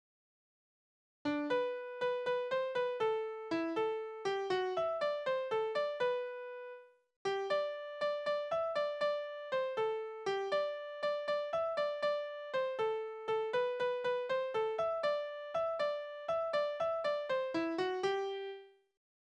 Liebeslieder:
Tonart: G-Dur
Taktart: 6/8
Tonumfang: große None
Besetzung: vokal